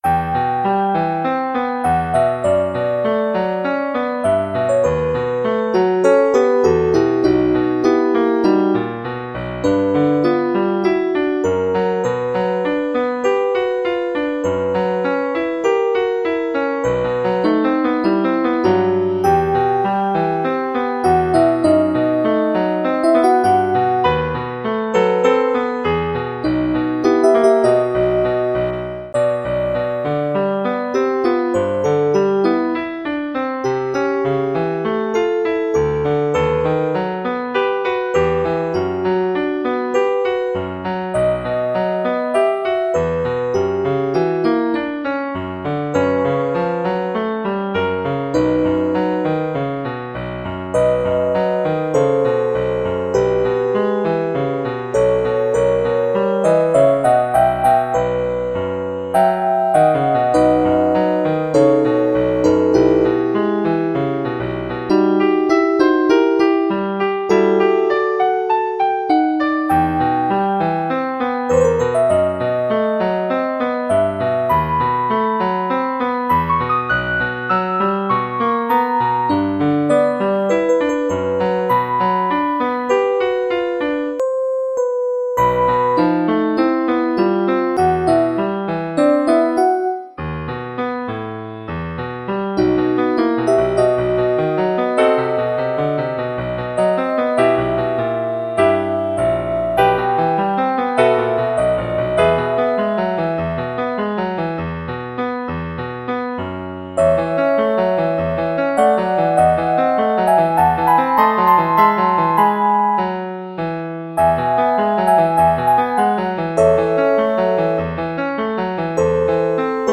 Electronic version